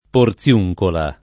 Porziuncola [ por ZL2j kola ]